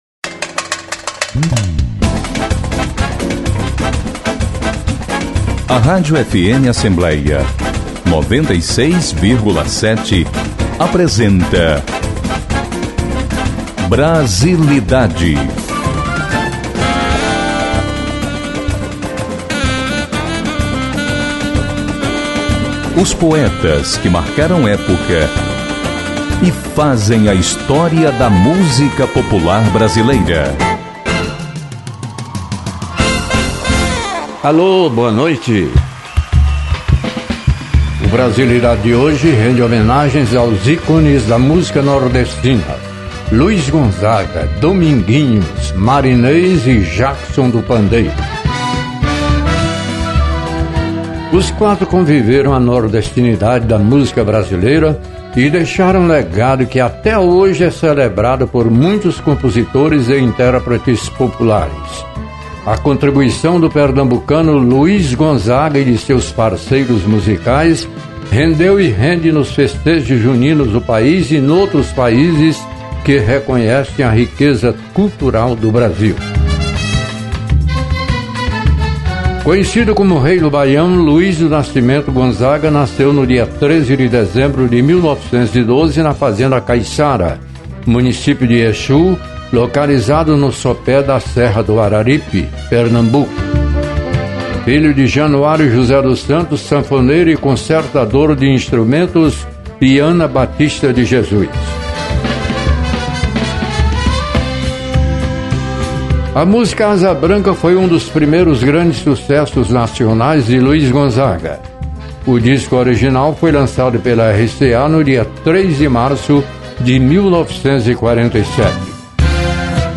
destaca a obra dos ícones da música nordestina